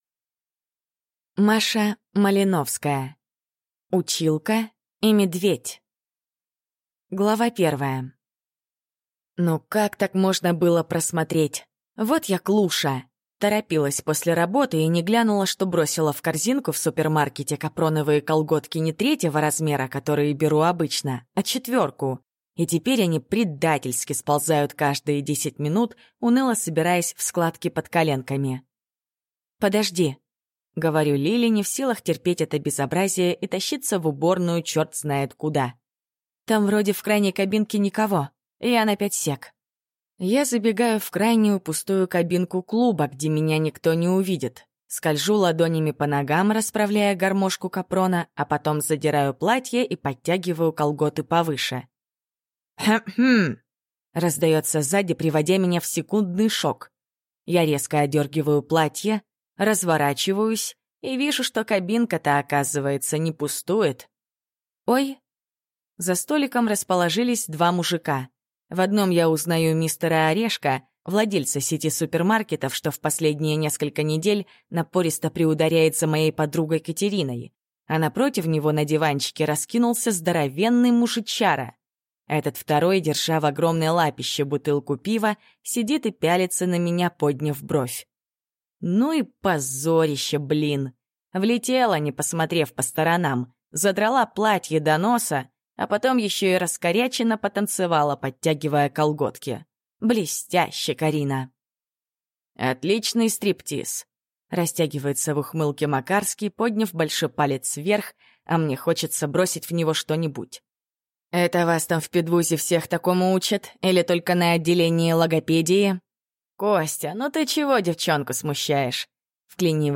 Аудиокнига Училка и медведь | Библиотека аудиокниг